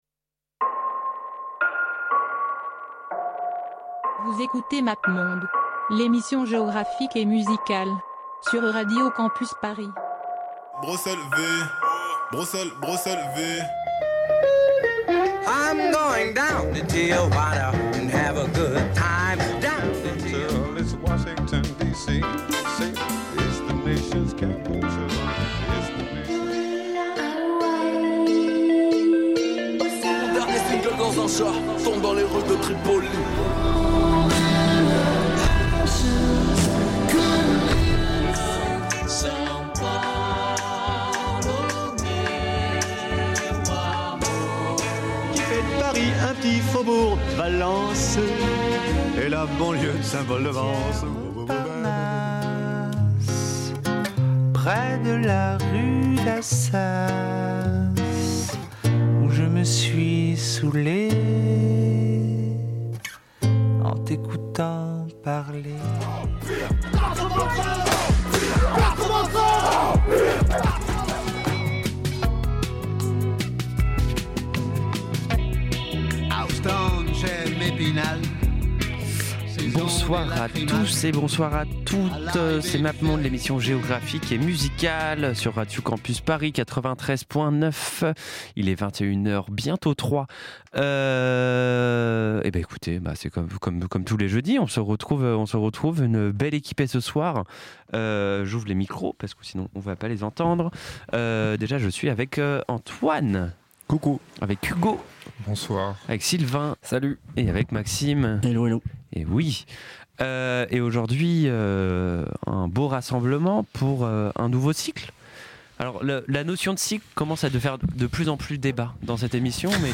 La musique nordique
Au menu : de la folk locale, du rock, de l’ambient avec des instruments acoustiques, de la noise, de la dub techno mais surtout pas mal de pop sous toutes ses formes.